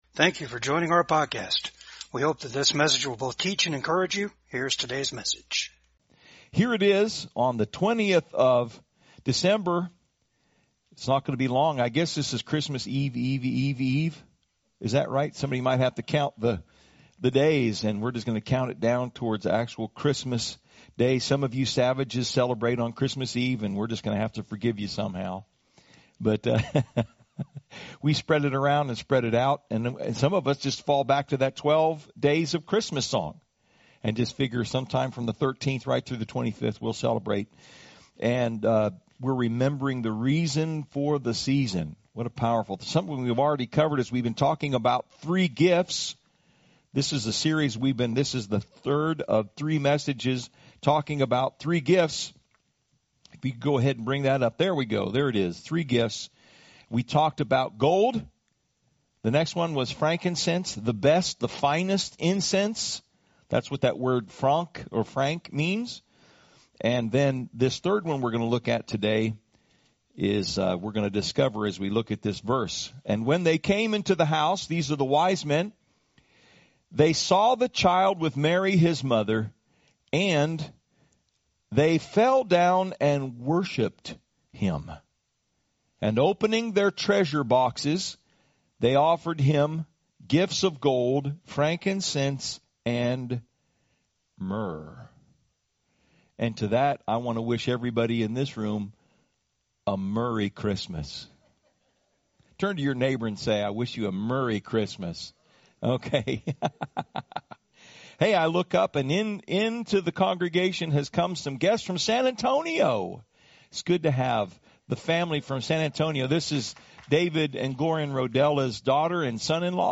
Matthew 2:11 Service Type: VCAG SUNDAY SERVICE PRESS PAST THE BITTER